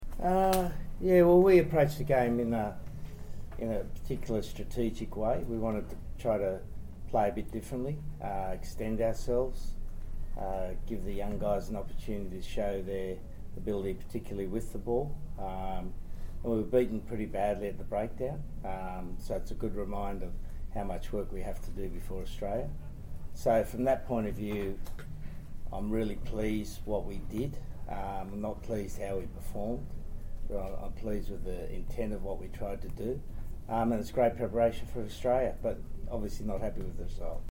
Eddie Jones - England vs Barbarians Post-Match Interview